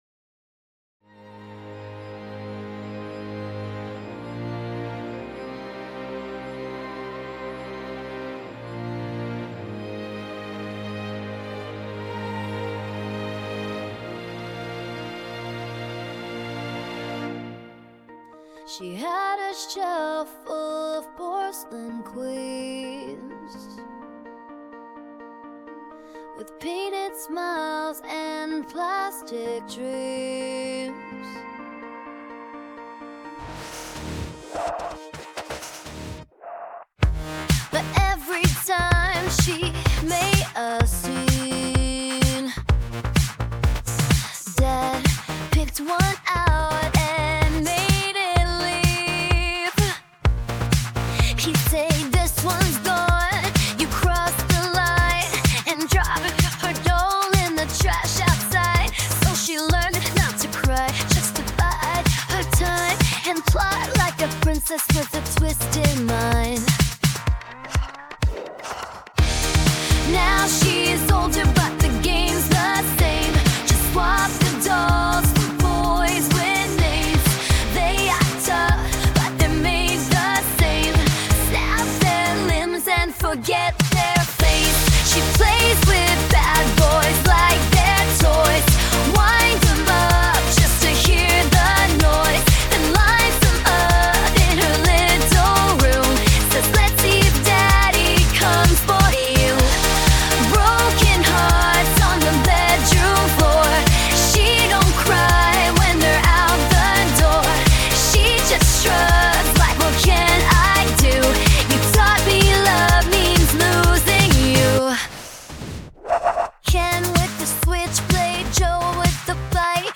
• Genre: Indie Pop/Electro